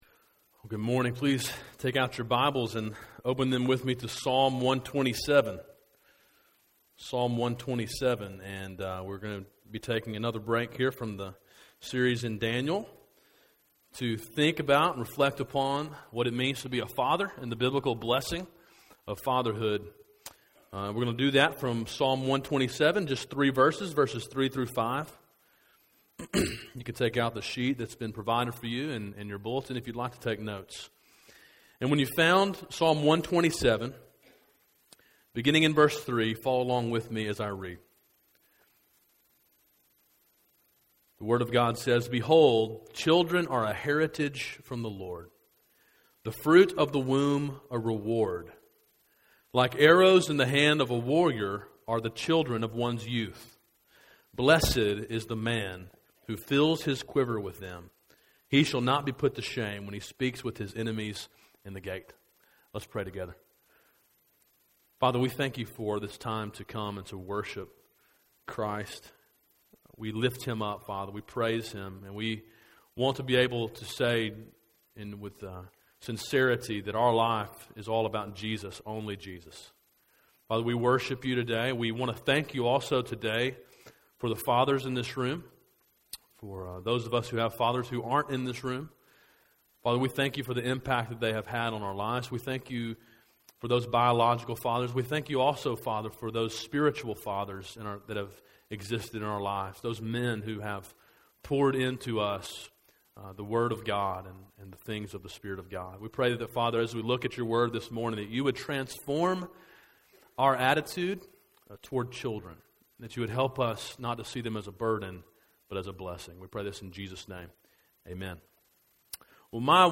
sermon-6-16-13-website.mp3